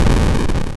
collapse_block_fall.wav